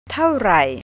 How much is this? taw-rai เท่าไหร่